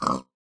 pig3.ogg